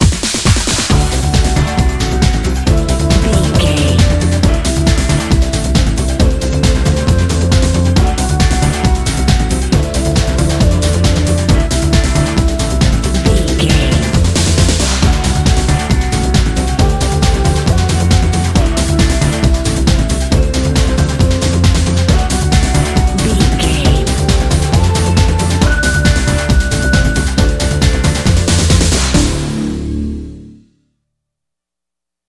Ionian/Major
Fast
synthesiser
drum machine